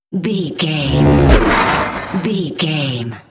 Scifi whoosh pass by
Sound Effects
futuristic
high tech
intense
vehicle